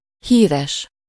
Ääntäminen
Synonyymit célèbre Ääntäminen France: IPA: [fa.mø] Haettu sana löytyi näillä lähdekielillä: ranska Käännös Ääninäyte Adjektiivit 1. híres Muut/tuntemattomat 2. nagyszerű 3. remek Suku: m .